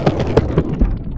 skatestop.ogg